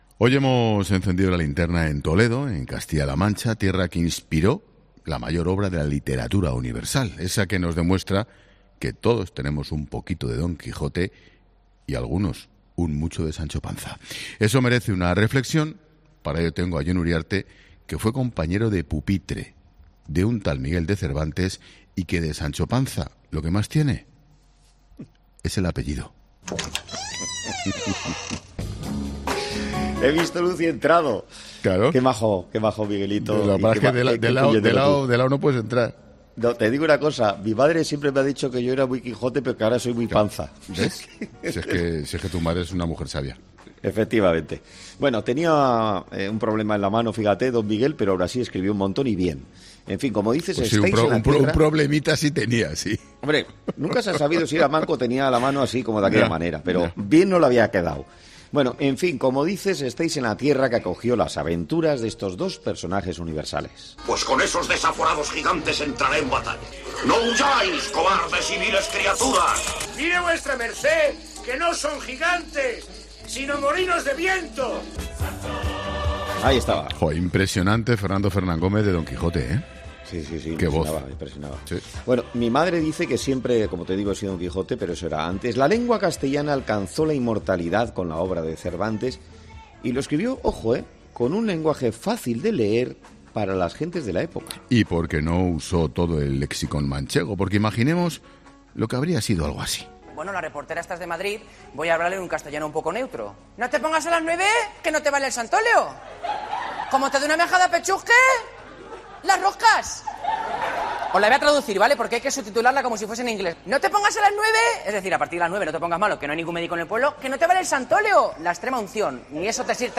El sonido daba paso a los clásicos cánticos del programa de Telecinco: “¡Tú sí que vales!